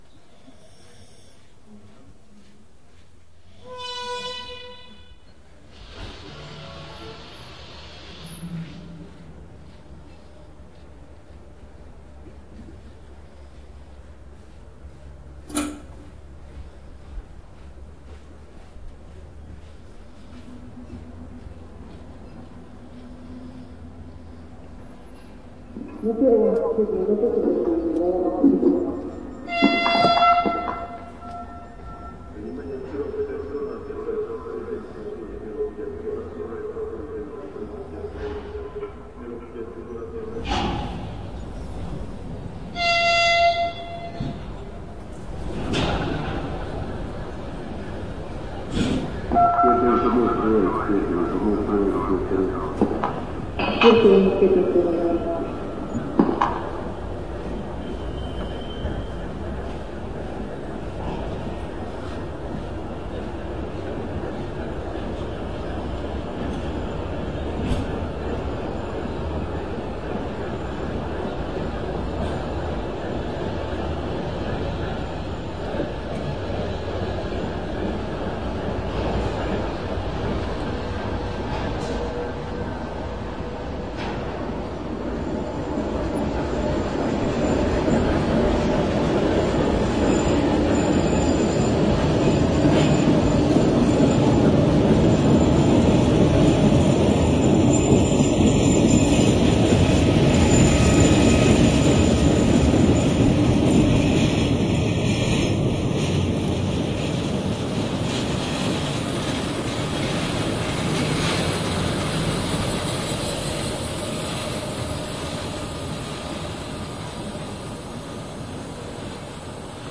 动物 " 奔跑的狗经过
描述：跑狗通过并停止。狗30公斤。
标签： 传递 关闭 跑步 停止 通过
声道立体声